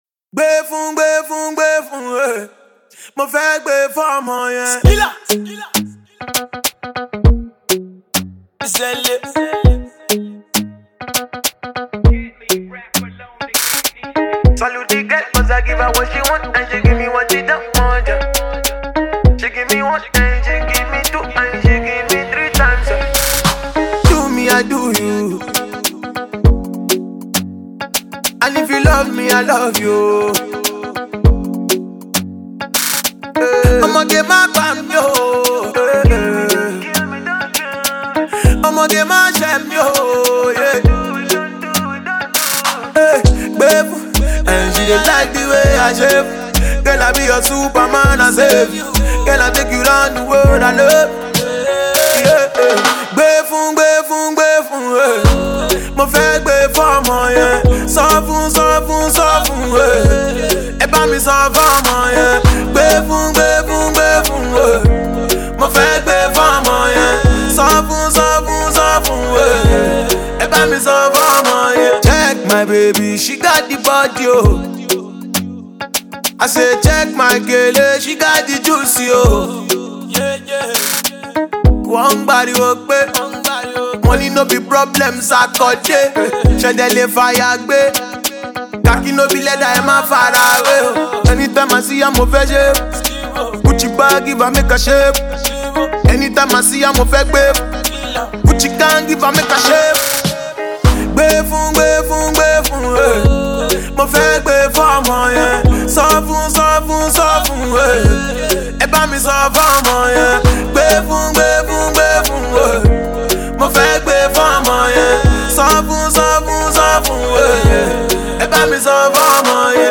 extraordinary and vintage musical piece